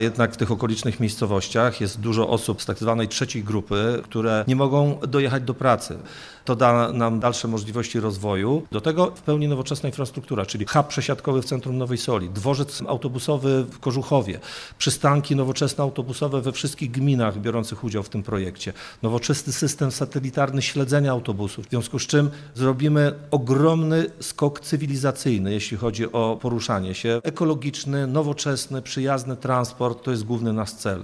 – Prawie trzydzieści autobusów będzie dowozić mieszkańców sześciu gmin do pracy i do szkół, bo nowosolskie zakłady pracy cierpią na brak pracowników – powiedział Wadim Tyszkiewicz, prezydent Nowej Soli: